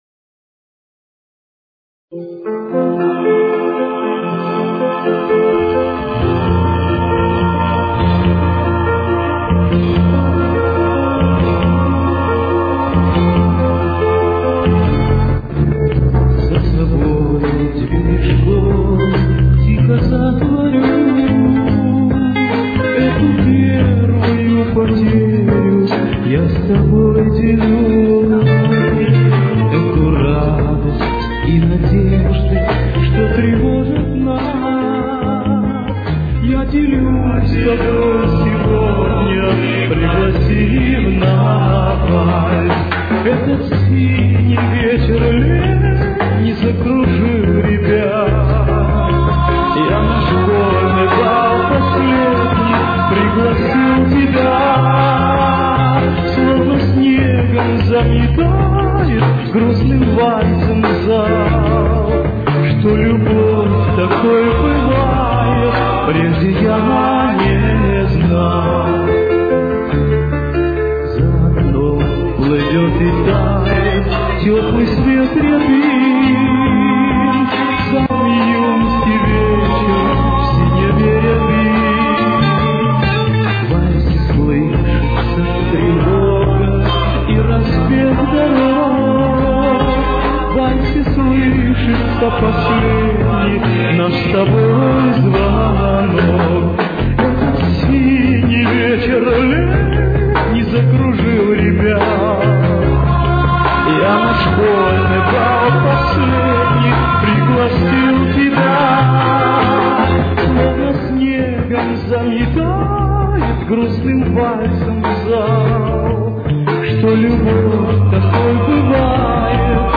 Тональность: Фа мажор. Темп: 74.